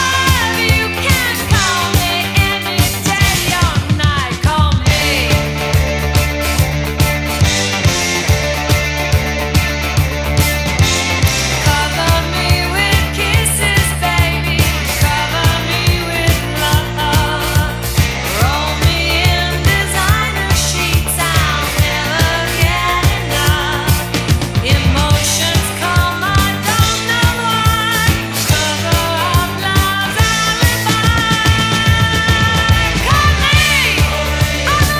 # Disco